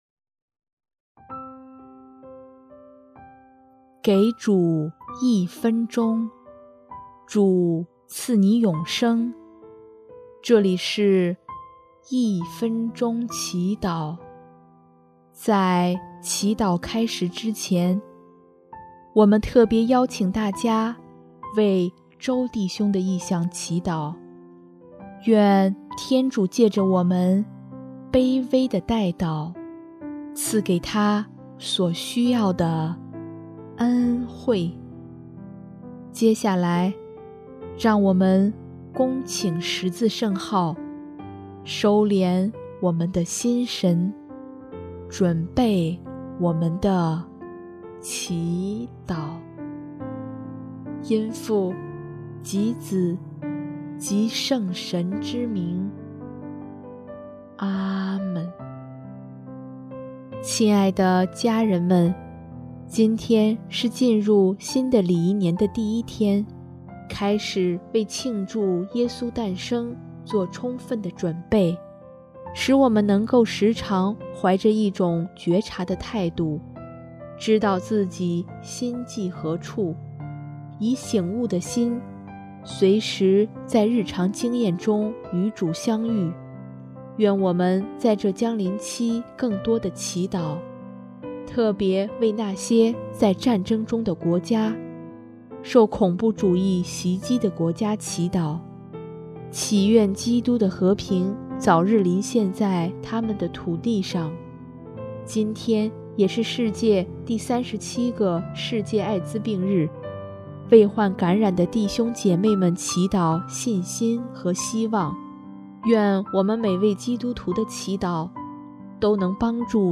【一分钟祈祷】|12月1日 特别为战争中的国家祈祷